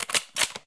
sounds_rifle_cock_02.ogg